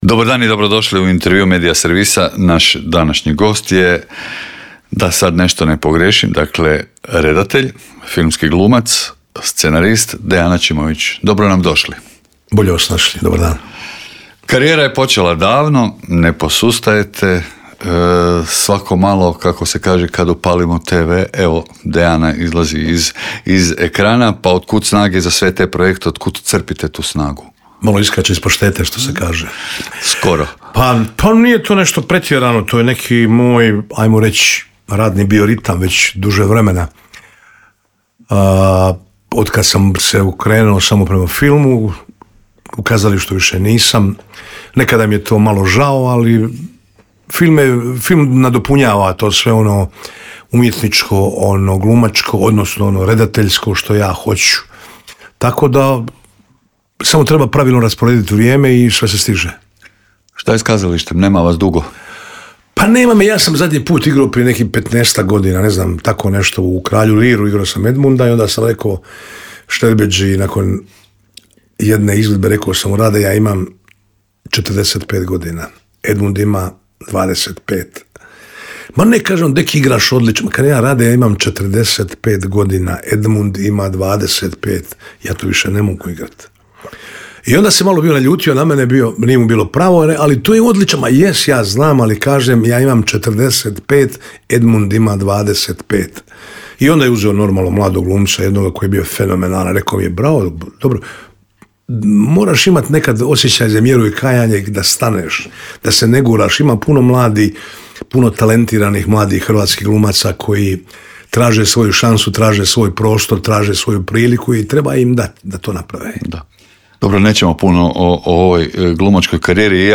ZAGREB - Gost intervjua Media servisa bio je Dejan Aćimović.